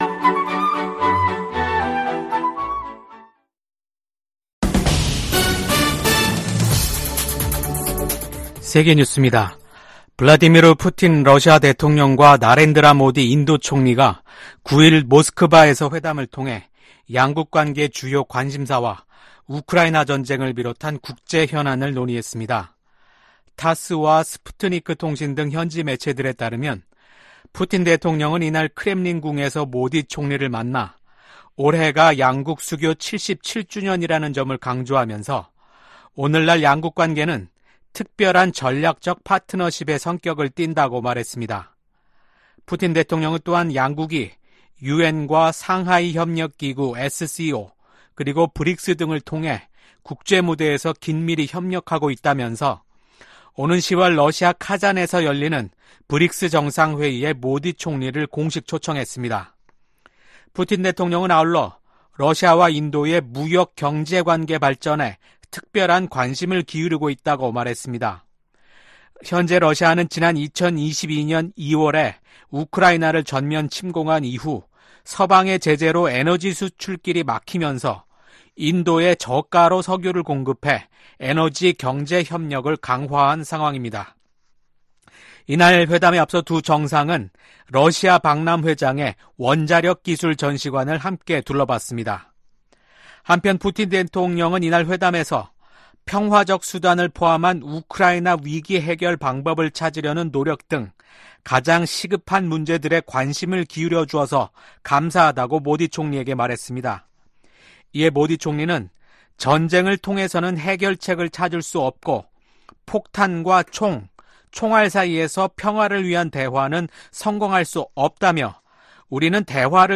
VOA 한국어 아침 뉴스 프로그램 '워싱턴 뉴스 광장' 2024년 7월 10일 방송입니다. 오늘 9일부터 11일까지 이곳 워싱턴에서는 32개국 지도자들이 참석하는 나토 정상회의가 열립니다.